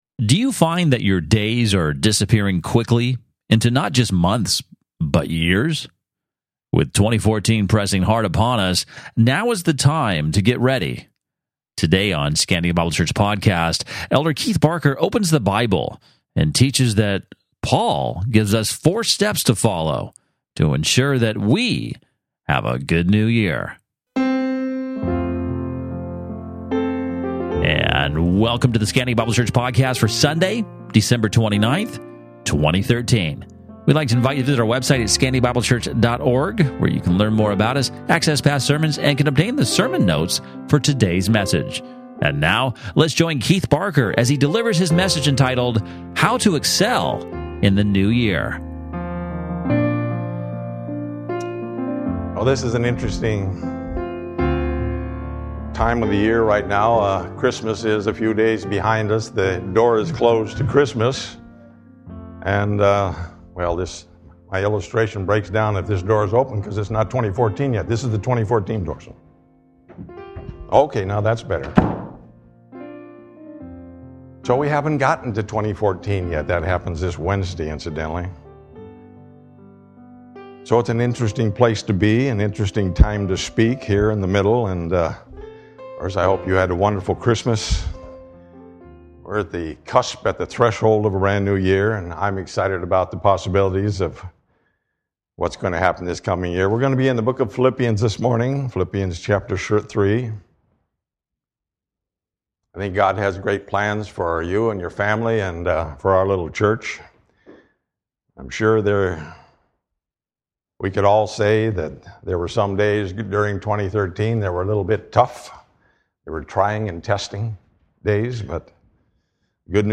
Sermon Notes Date